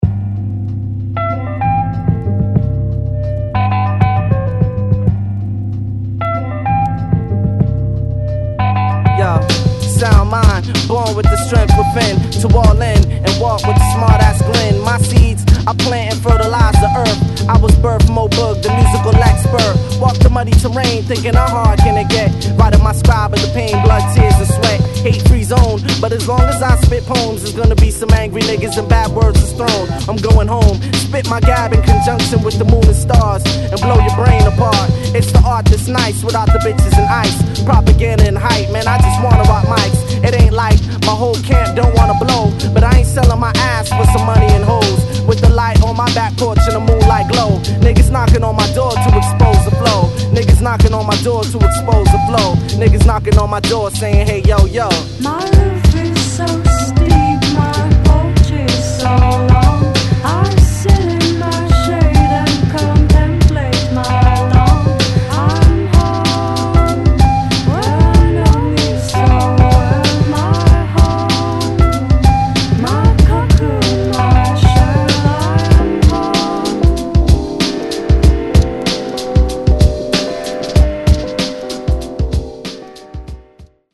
Jazzy Underground Hip Hop